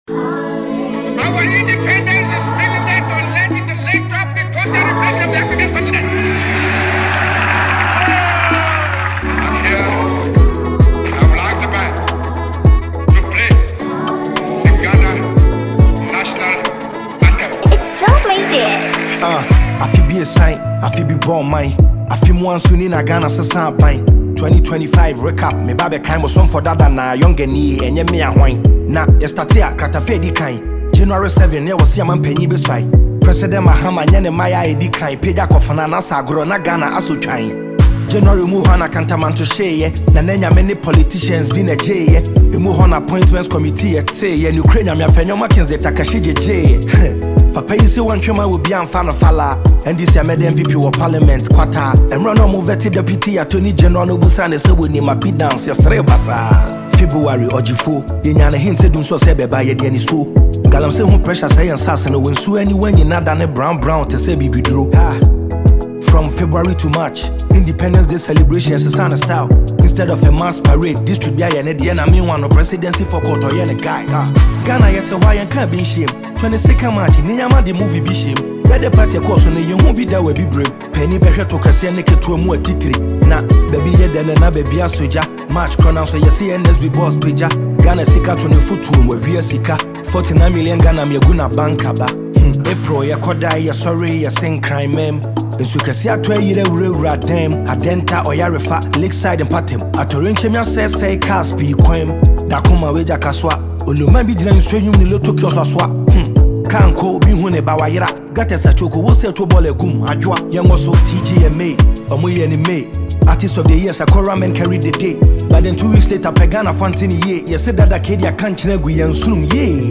The instrumental is simple yet powerful
Genre: Hip-Hop / Conscious Rap